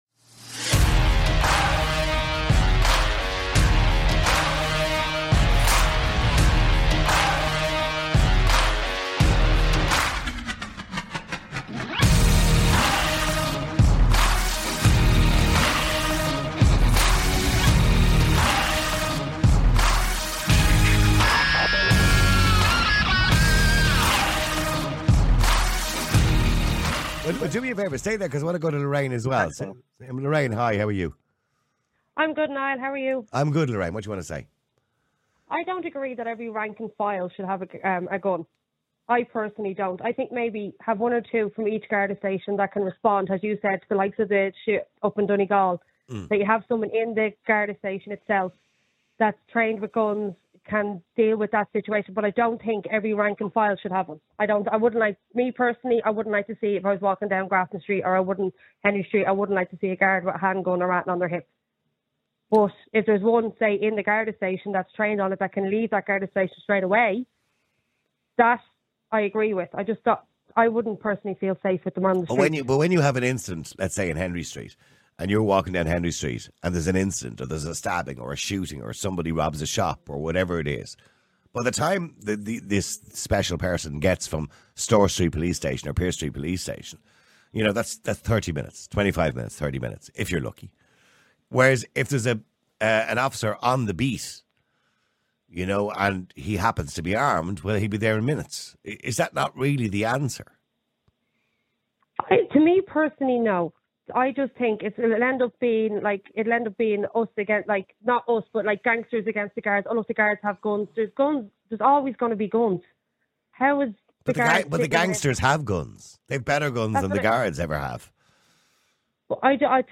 Some callers advocate for arming all Gardaí, emphasizing the need for law enforcement to have the necessary tools to combat rising criminal activity.